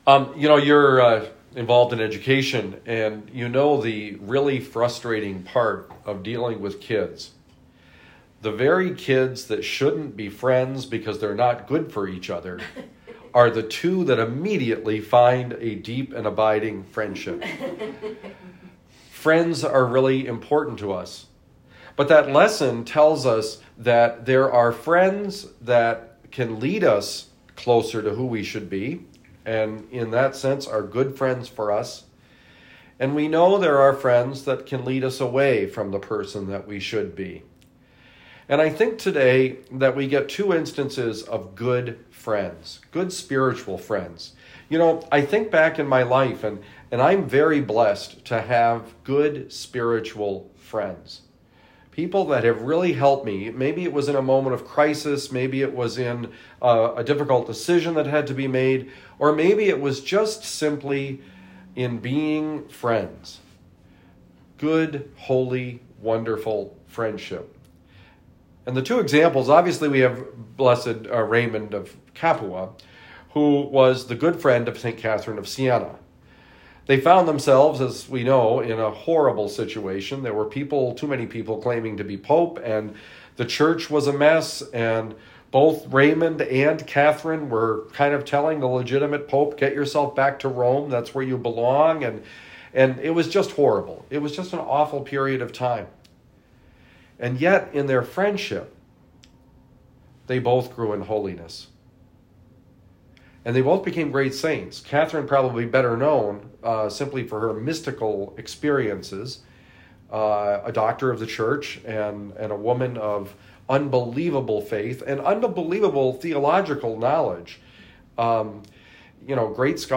Homily given at the Convent for the Dominican Sisters at Saint Joseph Parish, Cottleville, MO.